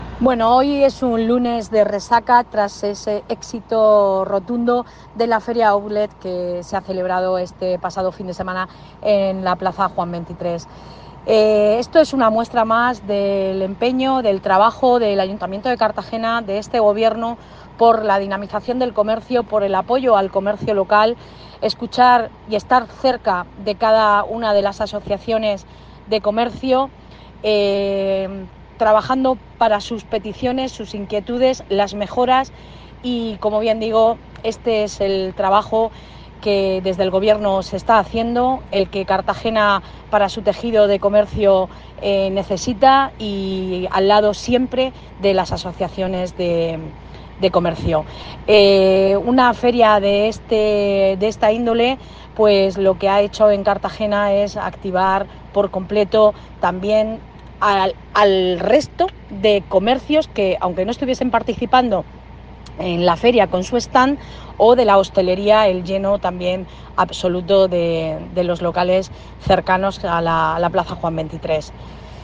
Enlace a Declaraciones de Belén Romero.